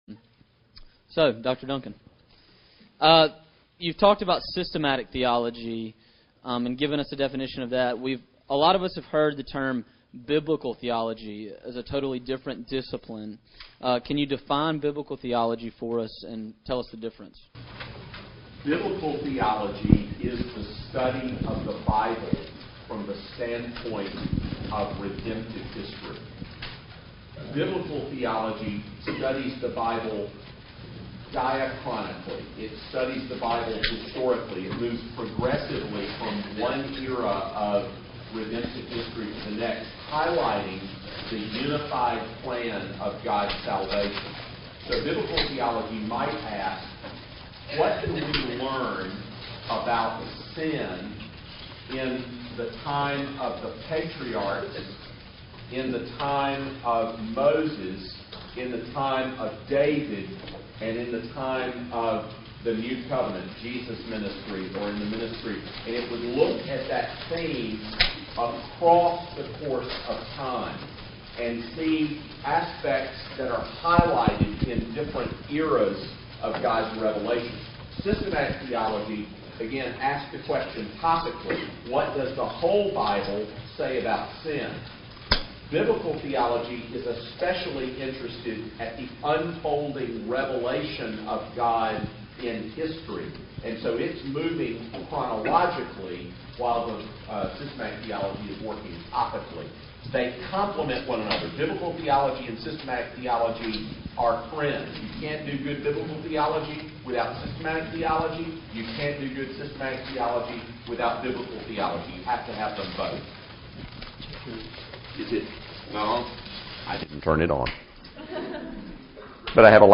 Question and Answers: Theology Proper: The Doctrine of God -Part 1
Q_and_A_Round1.mp3